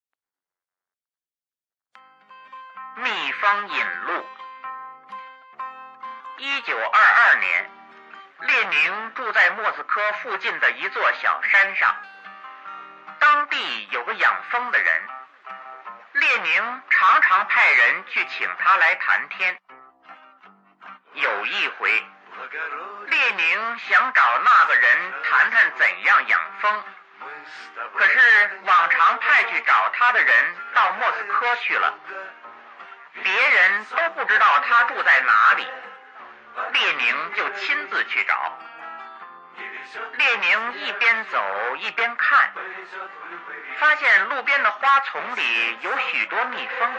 蜜蜂引路 课文朗读